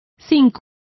Complete with pronunciation of the translation of zincs.